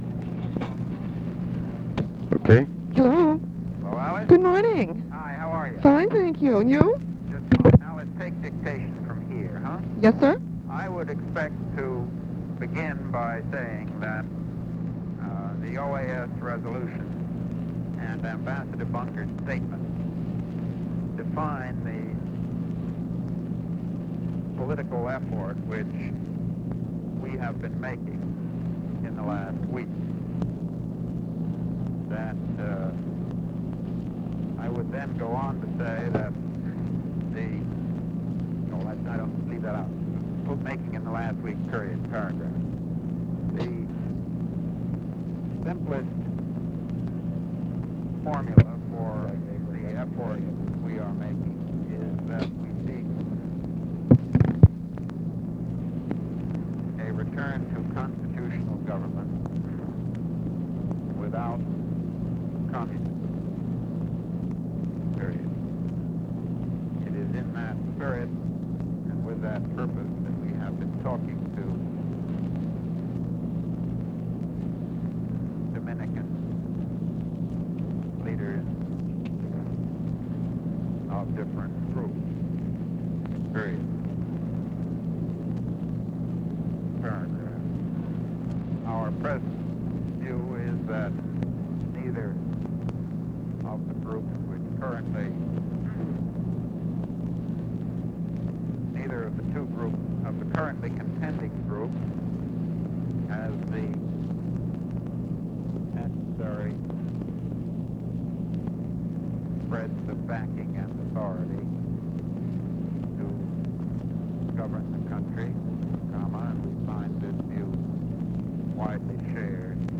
Conversation with MCGEORGE BUNDY and ABE FORTAS, May 20, 1965
Secret White House Tapes | Lyndon B. Johnson Presidency